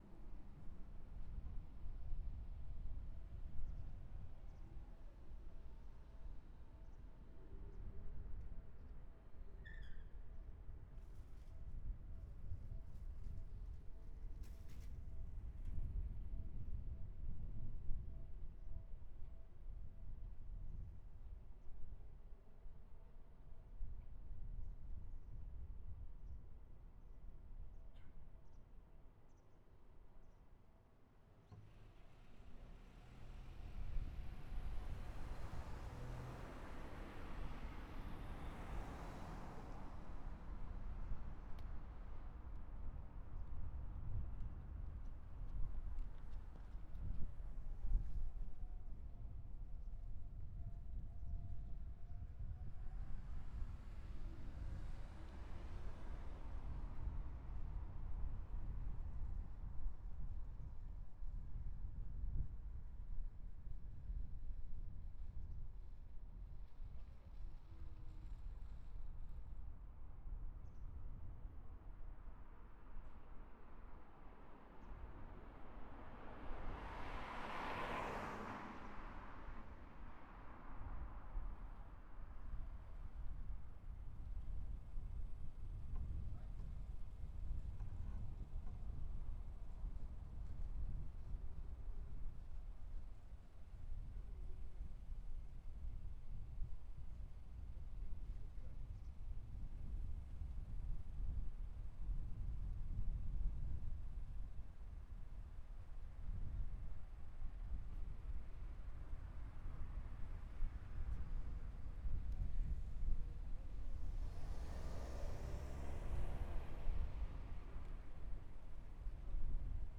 自宅玄関前で録音
レコーダーはPCM-D100、内蔵マイク
内蔵マイク　WIDEポジション
自宅前で、風が強い日です。
踏切方向に向けています。